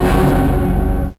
55bf-orc09-f#2.wav